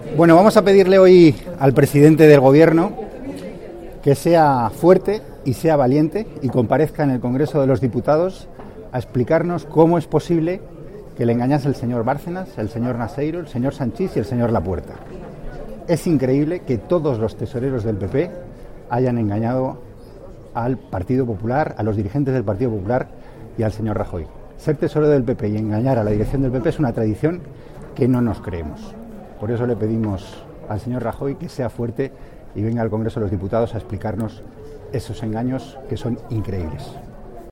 Declaraciones de Antonio Hernando antes de comenzar la Diputación Permanente para explicar por qué pediremos la comparecencia de Rajoy sobre Bárcenas y la financiación del PP 27/1/2015